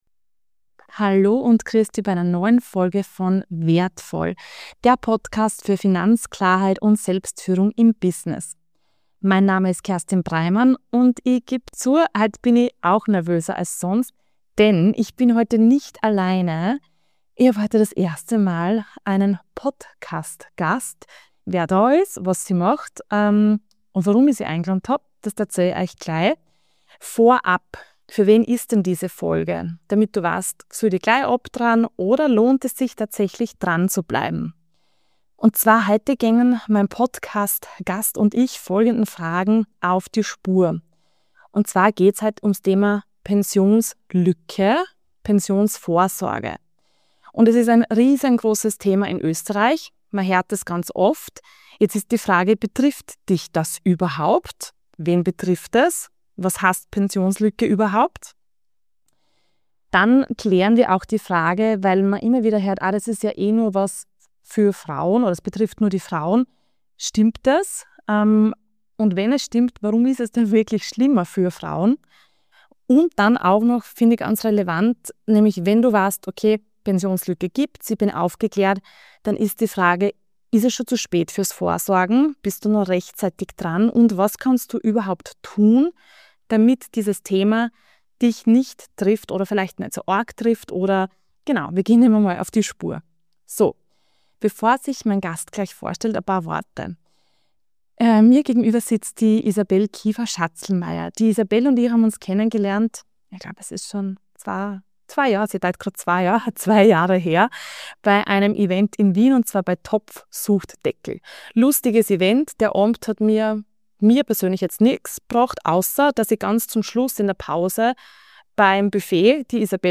Pensionslücke? Betrifft dich mehr, als du denkst - Interview